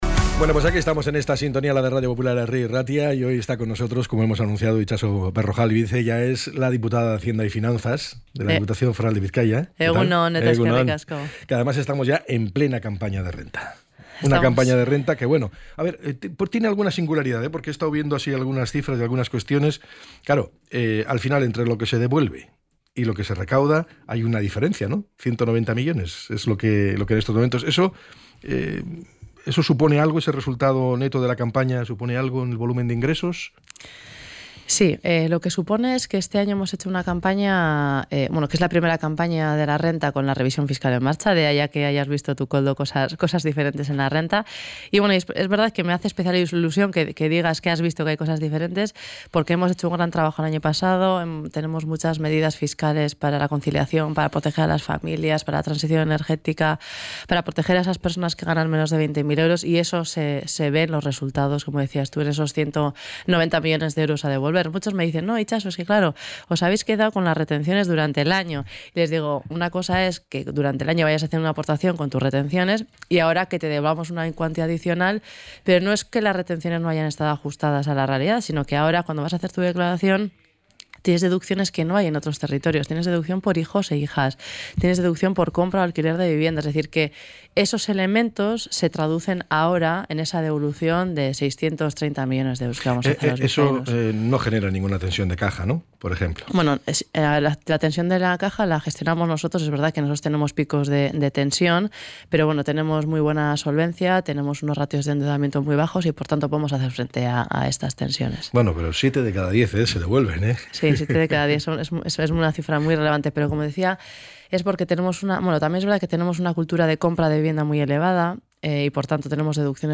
ENTREV.-ITXASO-BERROJALBIZ.mp3